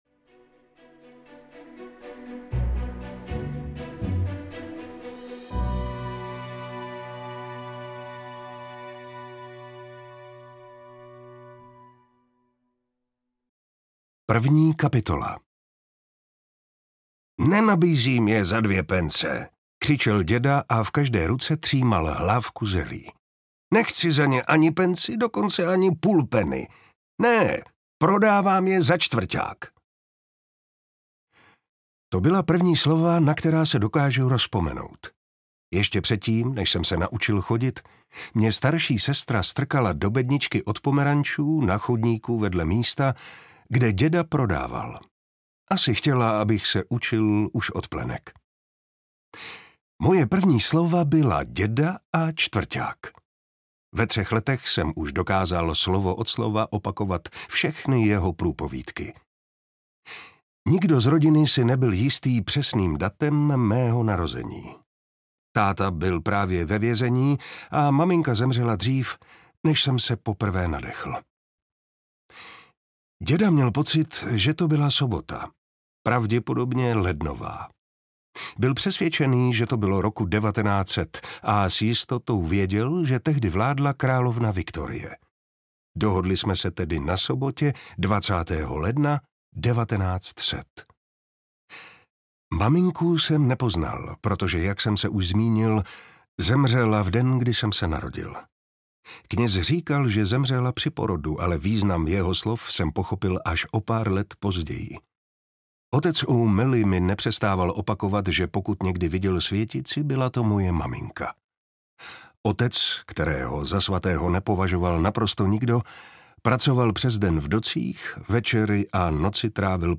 Zlatý Charlie audiokniha
Ukázka z knihy
• InterpretMartin Preiss